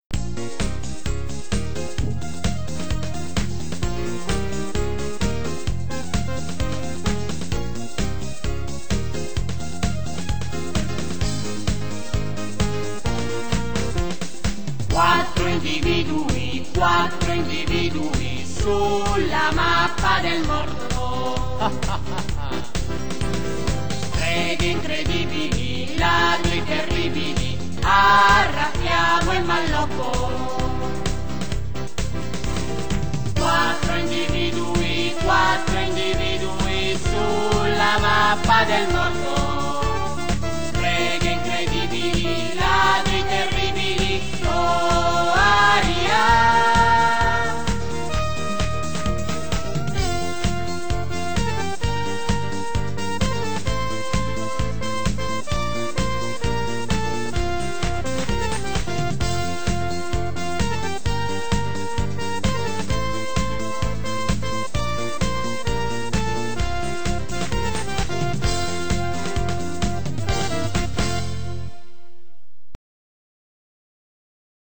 Trovate in questa sezione alcuni dei brani cantati durante lo spettacolo.